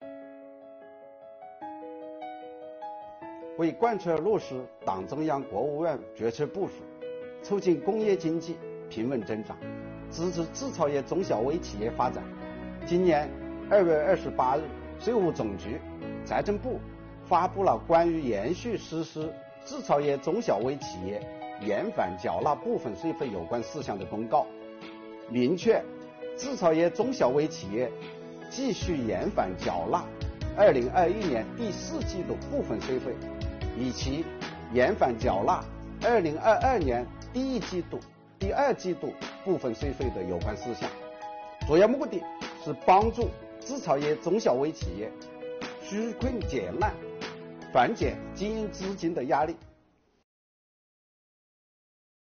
本期课程国家税务总局征管和科技发展司副司长付扬帆担任主讲人，对制造业中小微企业缓缴税费政策解读进行详细讲解，确保大家能够及时、便利地享受政策红利。